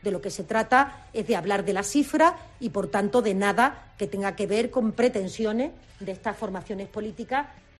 Le han preguntado tras el Consejo de Ministros por esta incongruencia a María Jesús Montero y ha utilizado dos argumentos.
Declaraciones de María Jesús Montero